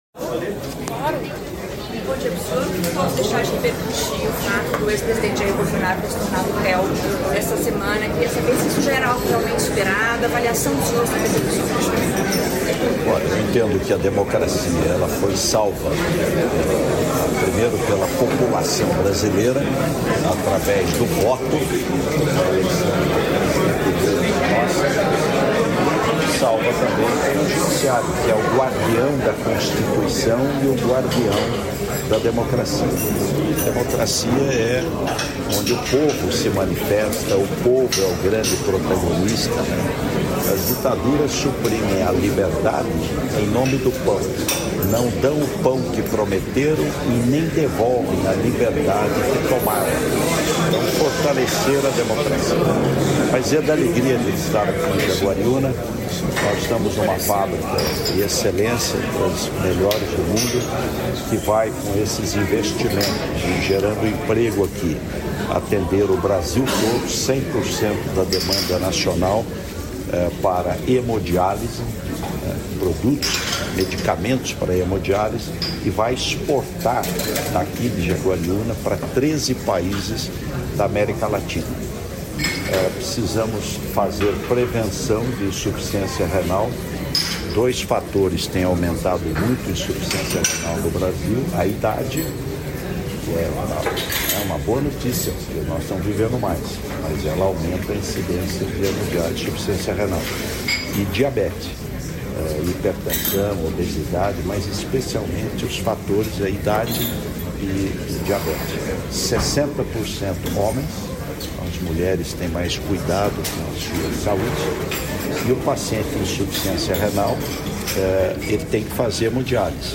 Íntegra da entrevista concedida a jornalistas pelos ministros Carlos Fávaro, da Agricultura e Pecuária; Renan Filho, dos Transportes; e Silvio Costa Filho, de Portos e Aeroportos, nesta terça-feira (25), em Tóquio (Japão).